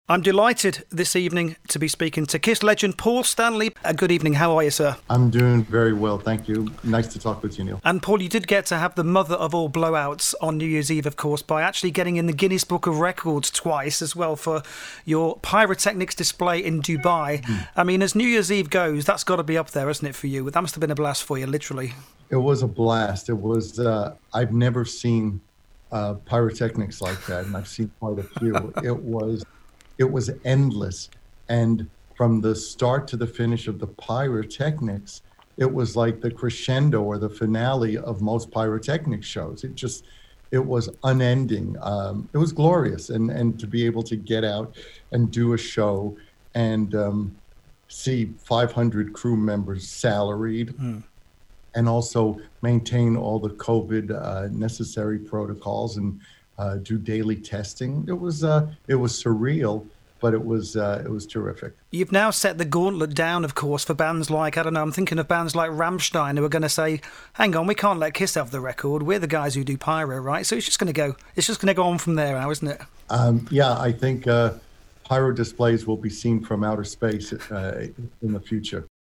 Featured Interview